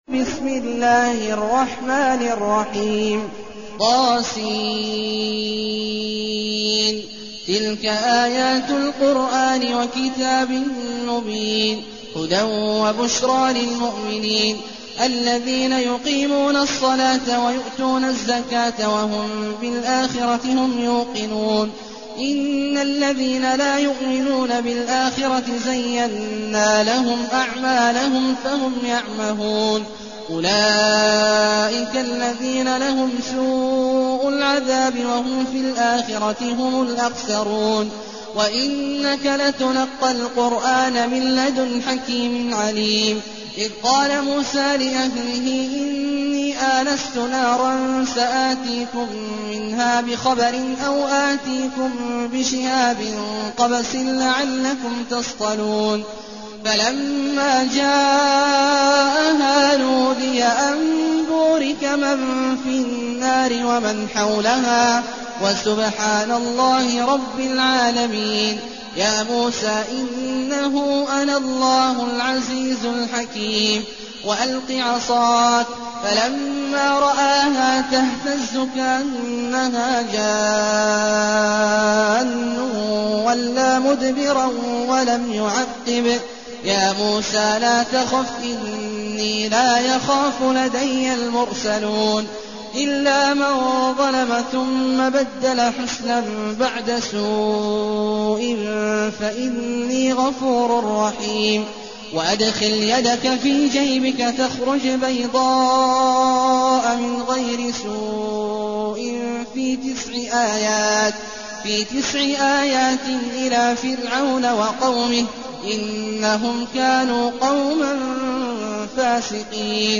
المكان: المسجد النبوي الشيخ: فضيلة الشيخ عبدالله الجهني فضيلة الشيخ عبدالله الجهني النمل